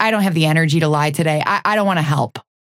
Vyper voice line - I don't have the energy to lie today: I don't wanna help.